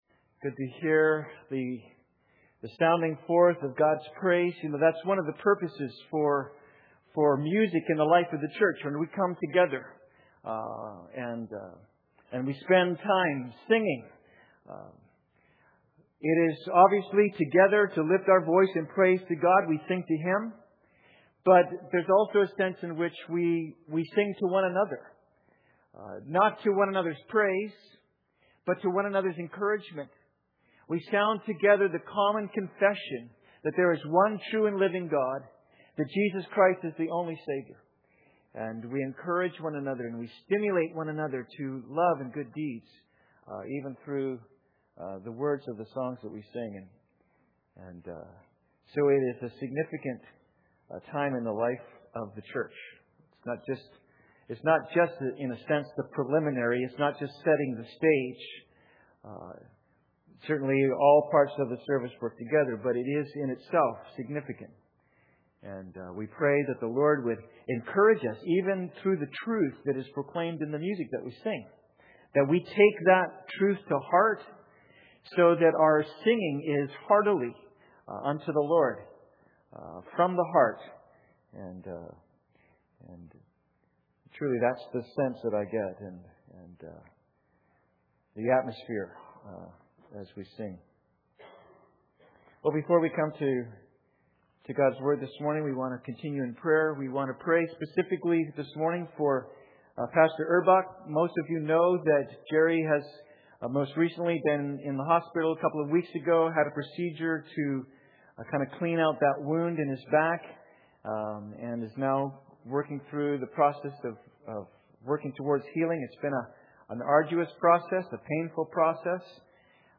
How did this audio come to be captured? Passage: Philippians 1:9-11 Service Type: Sunday Service